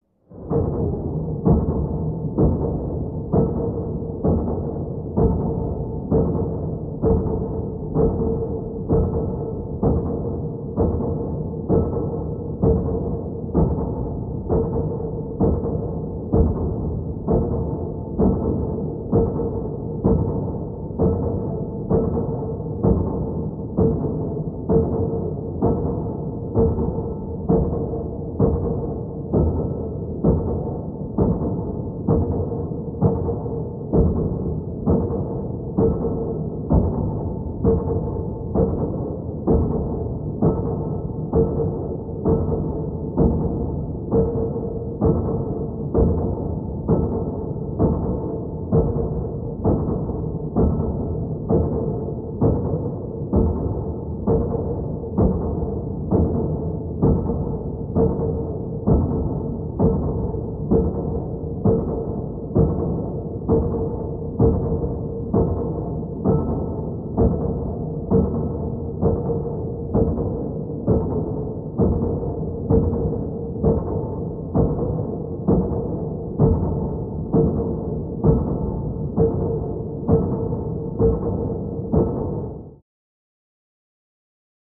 Ambiance, Machine Pulse; Rhythmic Reverberant Pounding Of Heavy Metal Machine